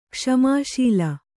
♪ kṣamā śila